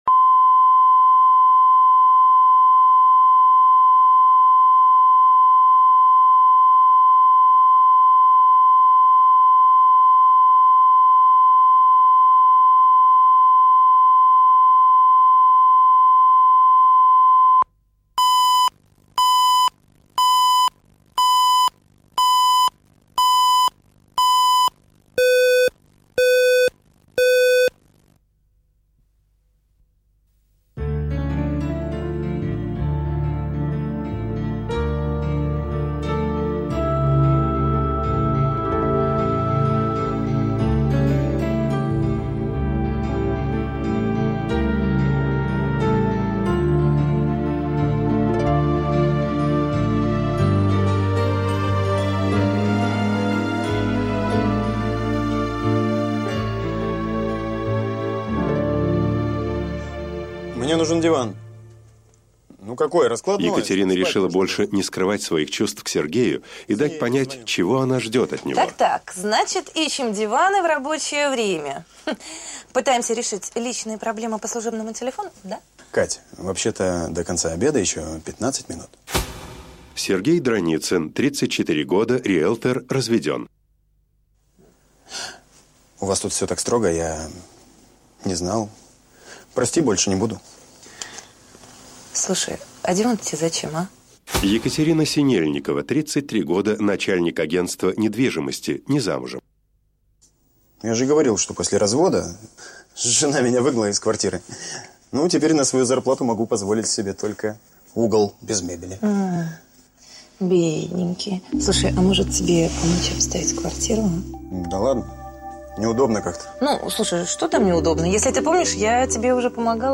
Аудиокнига Старая обида